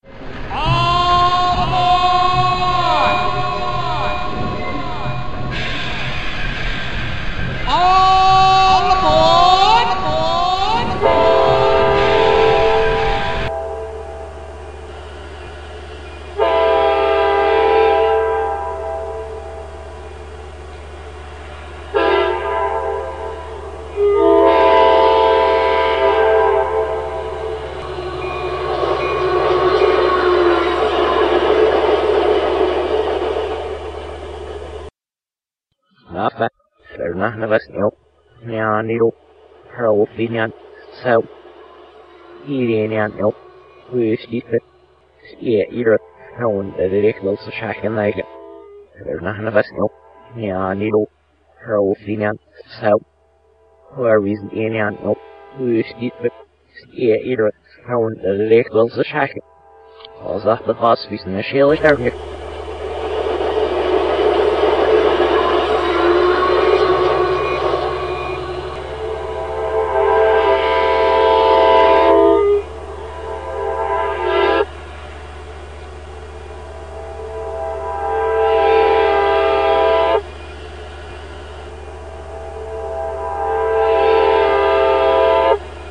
Please note that this is an audio puzzle.
calamity_line_railway.mp3